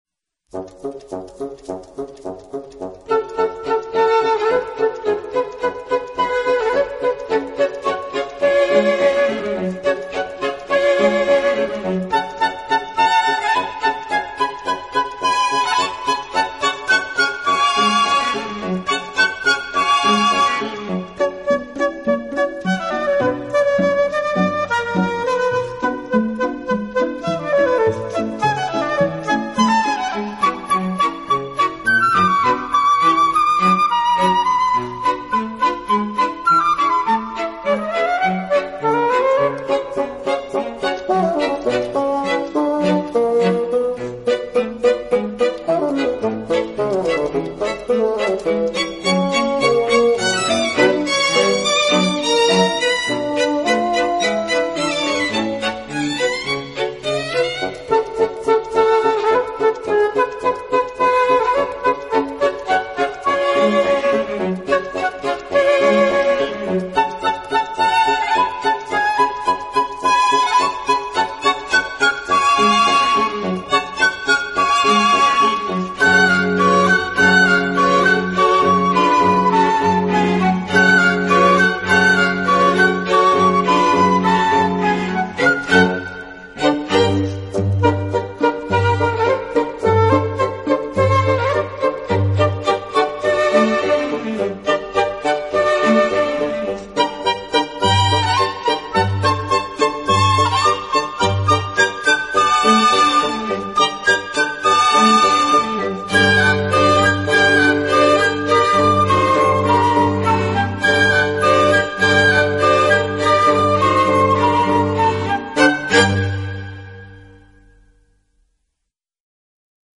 音乐类型：Classical
长笛的音色清冷高雅，宛如一位冰雪美人，低调中透着活泼明丽，圆润而绚丽，
经典长笛名曲，专业数码录音，不可多得的精选。